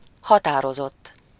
Ääntäminen
Vaihtoehtoiset kirjoitusmuodot (vanhentunut) determin'd Synonyymit dogged set steadfast strong resolved purposeful resolute strenuous unflinching Ääntäminen CA UK US UK : IPA : /dɪˈtɜː(ɹ)mɪnd/ GenAm: IPA : /dɪˈtɝmɪnd/